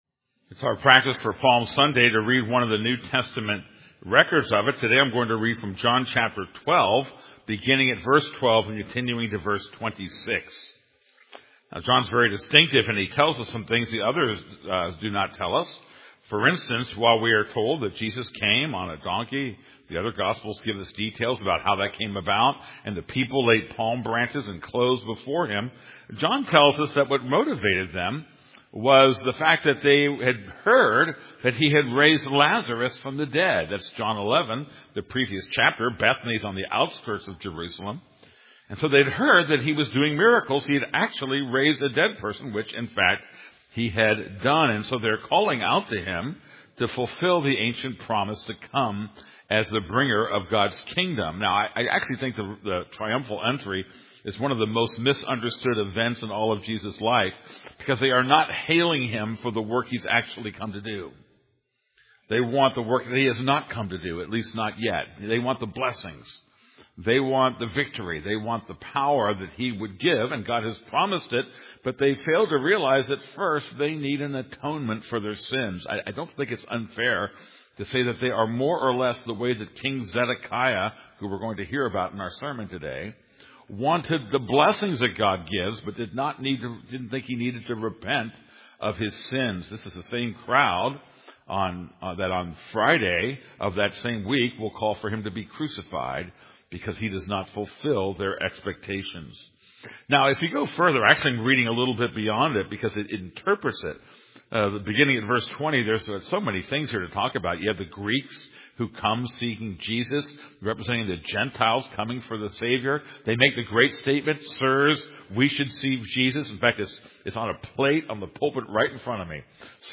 This is a sermon on John 12:12-26.